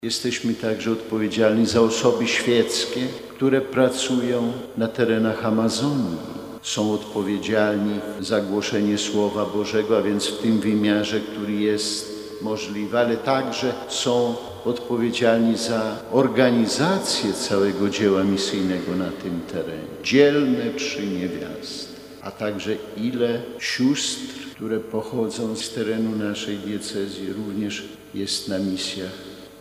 Centralnym punktem obchodów Nadzwyczajnego Miesiąca Misyjnego w diecezji warszawsko-praskiej była 24 października uroczysta msza św. w bazylice katedralnej św. Michała Archanioła i św. Floriana Męczennika.